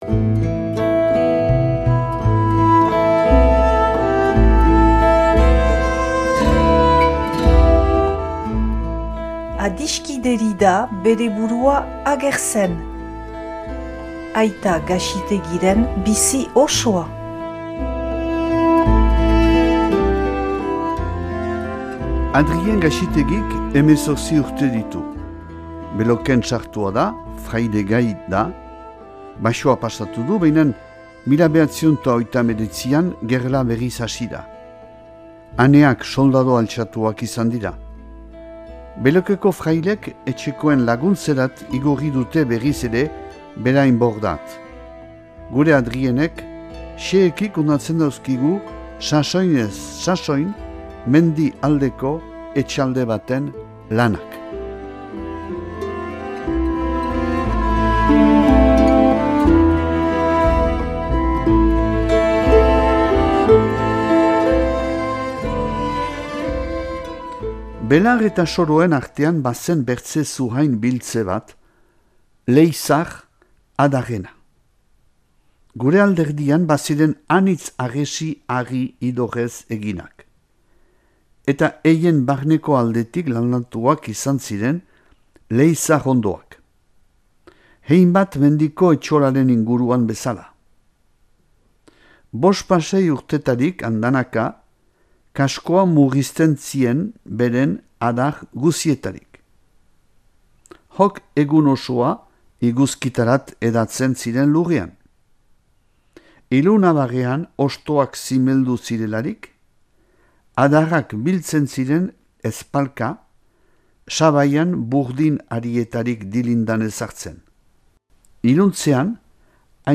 irakurketa bat dauzuegu eskaintzen.